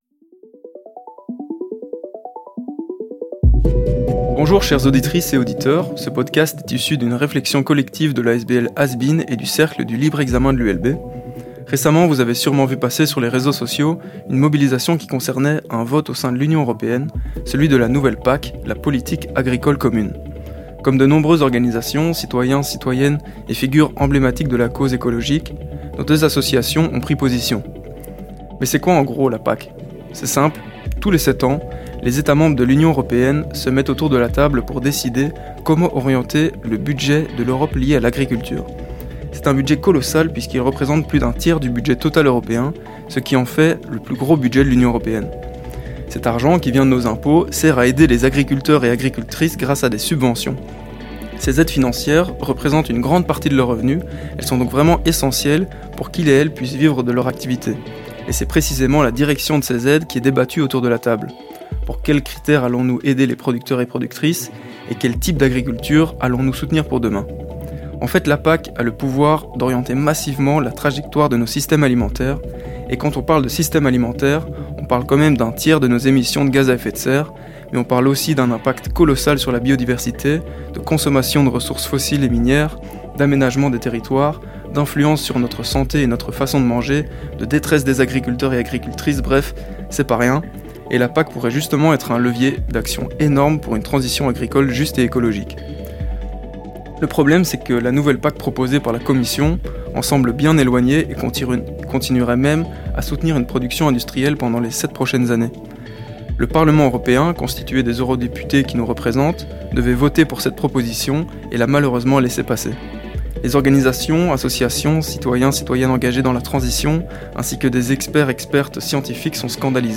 Ce premier épisode est une discussion à propos d’un levier d’action essentiel pour la transition écologique, la Politique agricole de l’union européenne avec notre intervenant